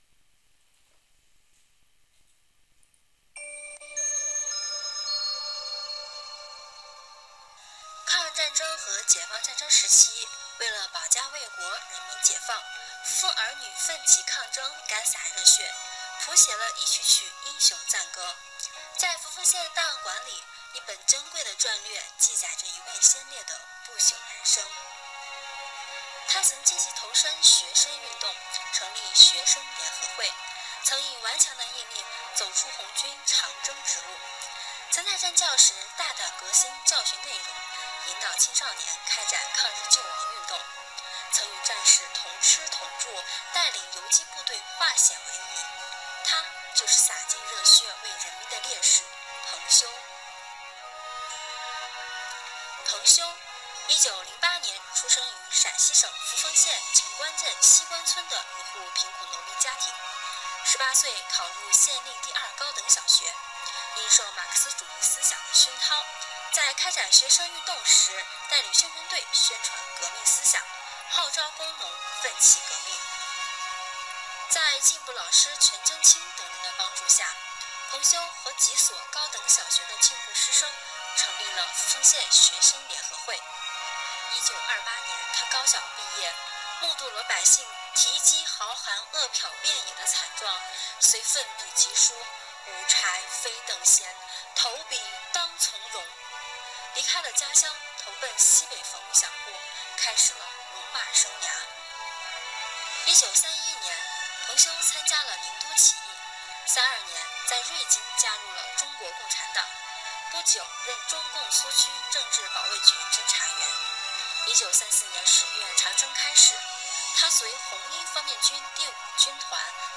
【红色档案诵读展播】洒尽热血为人民的烈士——彭修